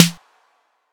Snares
TR808SN1.wav